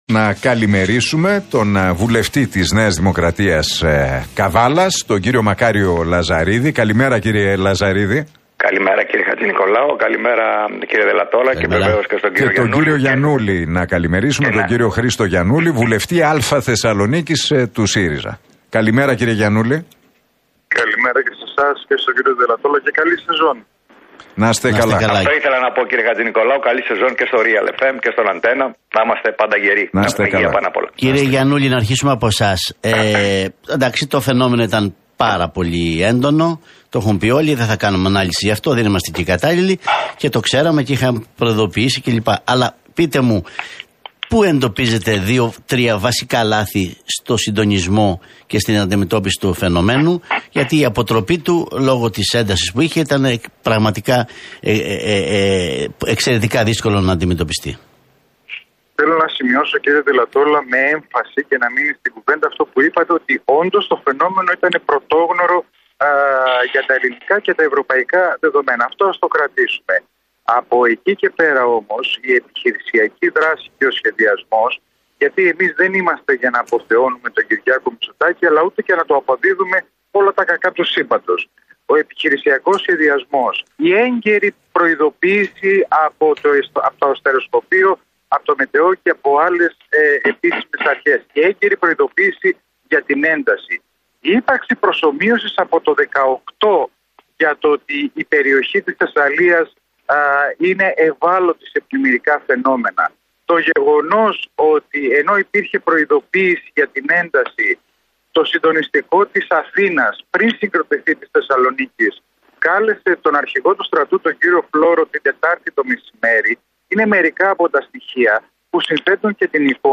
Debate Λαζαρίδη - Γιαννούλη στον Realfm 97,8